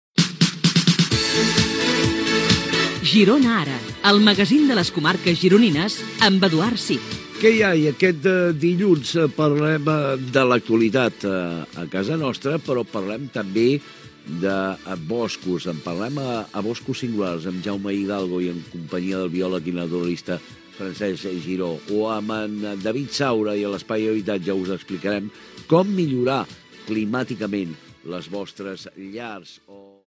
5c1d69ce4f8c3d11be5f976ce33d37347f4e0b9f.mp3 Títol Fem Girona Emissora Fem Girona Titularitat Pública municipal Nom programa Ara Girona (Fem Girona) Descripció Careta del programa i sumari de continguts.
Info-entreteniment